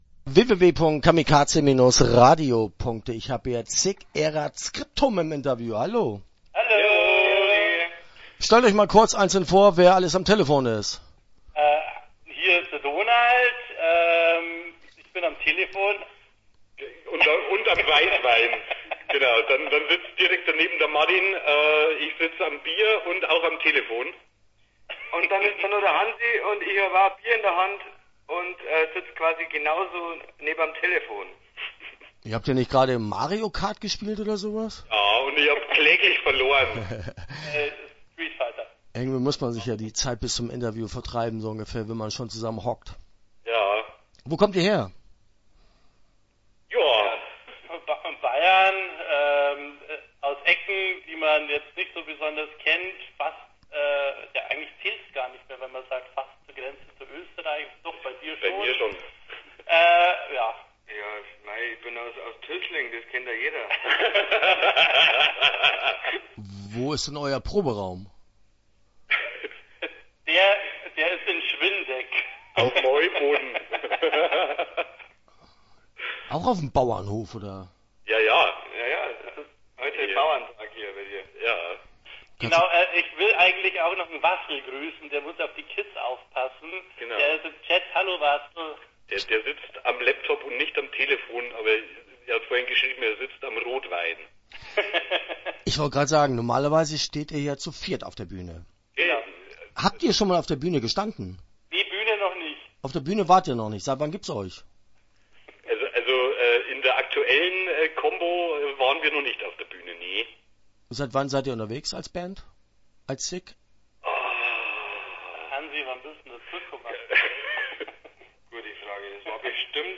[sic] sic erat sciptum - Interview Teil 1 (10:31)